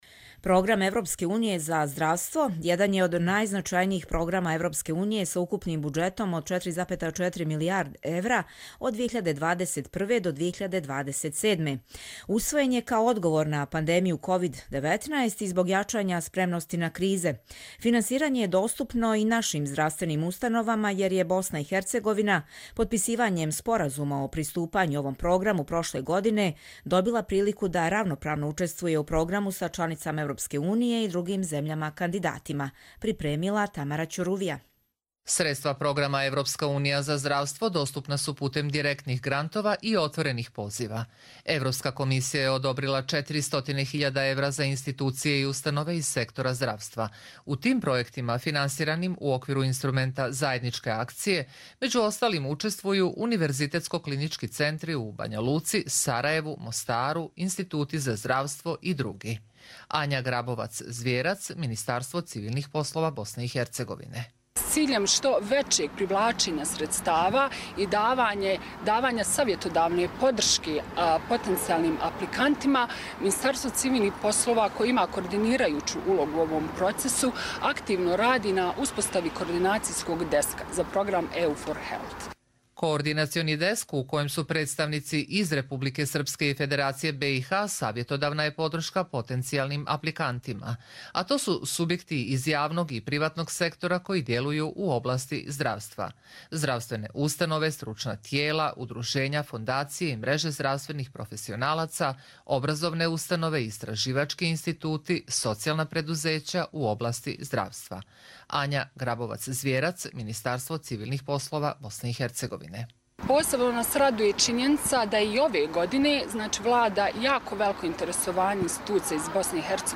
Радио репортажа